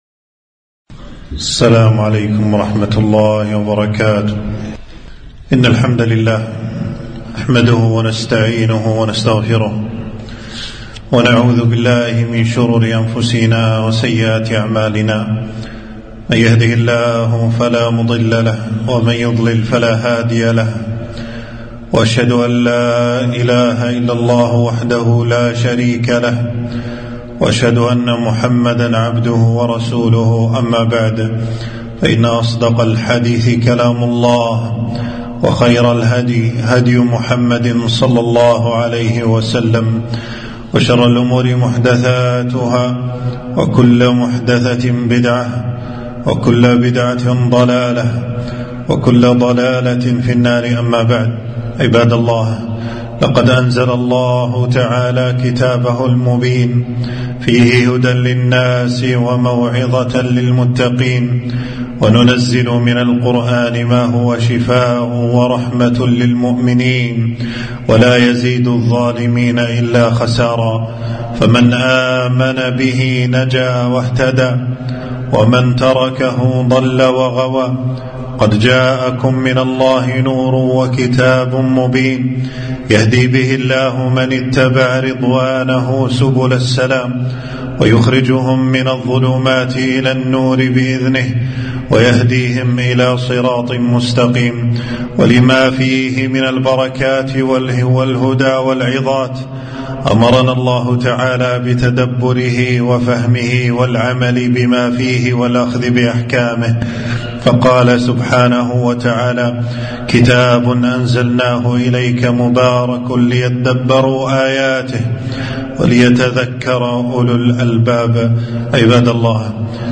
خطبة - قل هو الله أحد